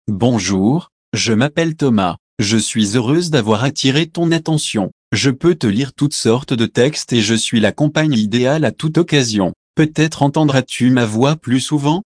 Professionelle Sprachausgabe zum Vorlesen und Vertonen beliebiger Texte
Professionelle, natürlich klingende männliche und weibliche Stimmen in vielen Sprachen, die kaum mehr von einem menschlichen Sprecher zu unterscheiden sind.